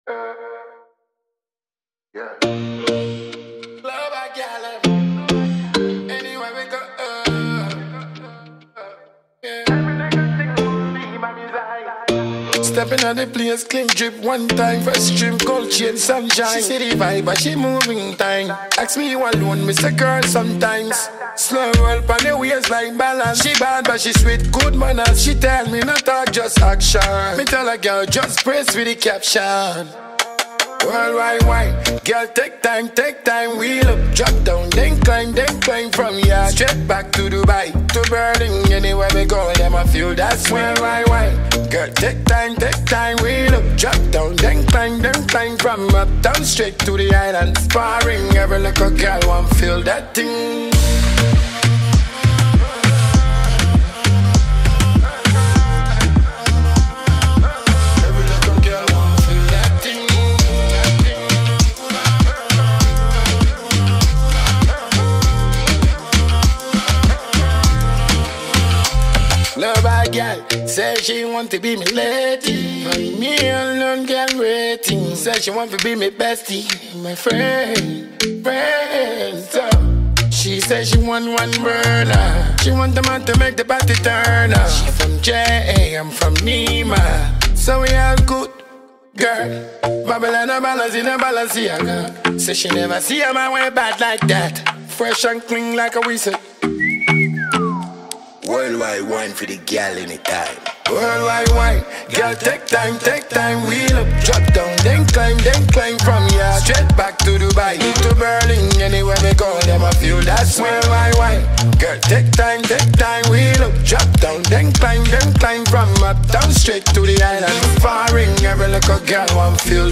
energetic and global-ready anthem